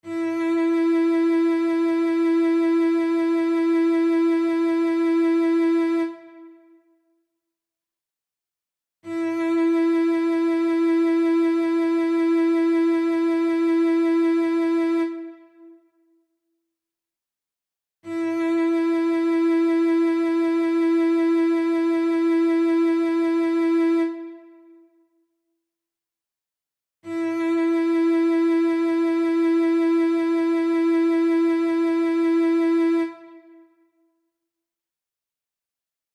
Listen to the note ... this is a little lower than the 3rd note, sing it loudly!